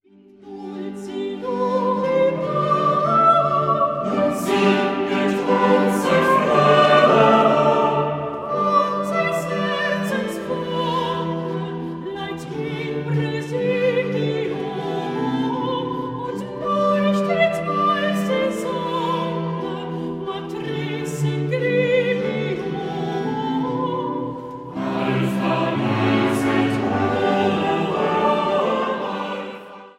Motette für sechs Stimmen